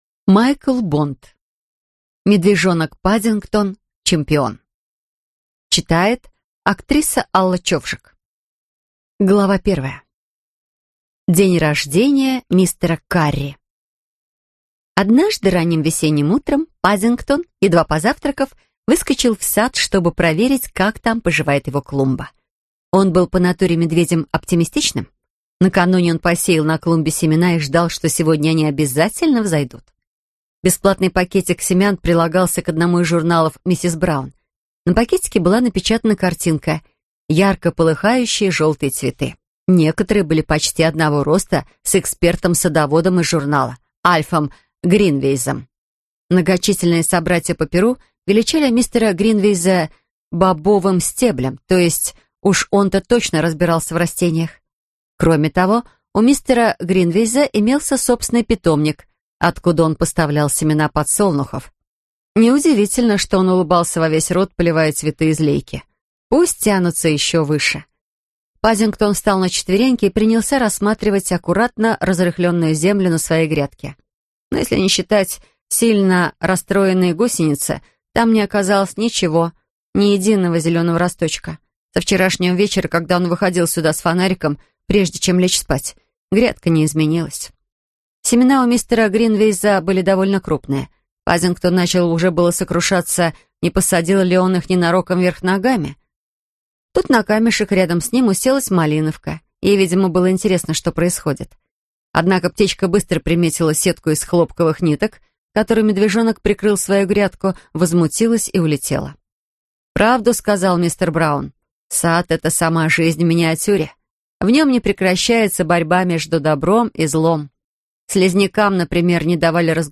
Аудиокнига Медвежонок Паддингтон – чемпион | Библиотека аудиокниг